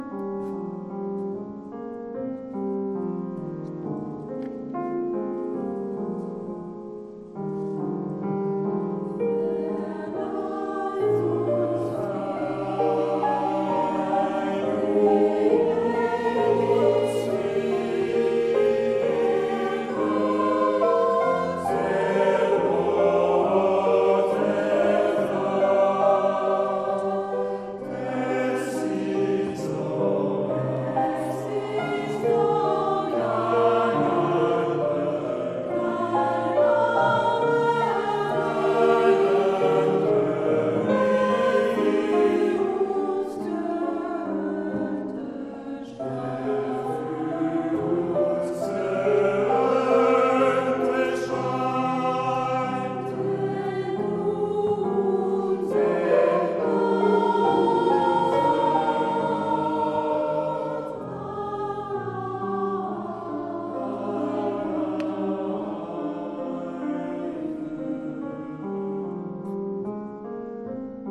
Concerts du 24 mai 2025 en l’église St Nicolas de Saint Arnoult en Yvelines et du 25 mai 2025 au Temple de Rambouillet